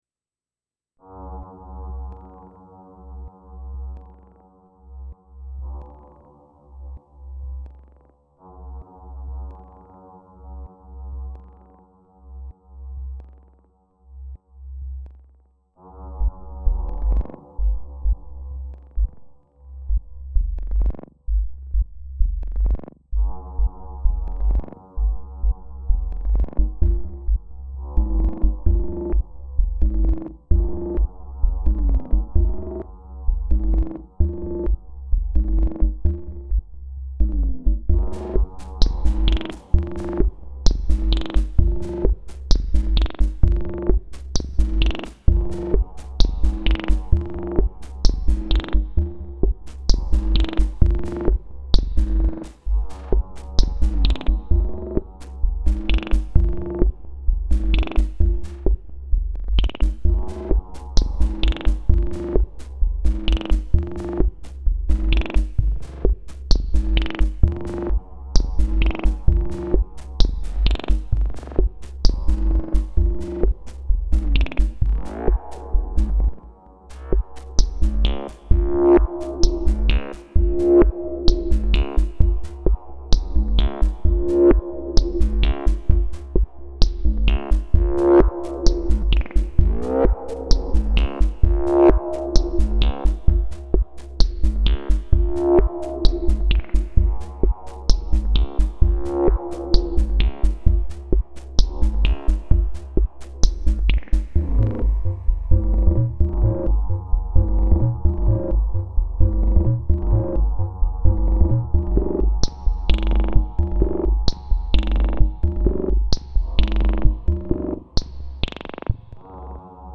Lots of p-locks and probability, delay through FX track.
Edit: beware of feedback loops and obnoxious frequencies.
:face_with_peeking_eye: There are also a few conflicting bass frequencies taking a lot of energy here, so the overall volume of the recording seems low.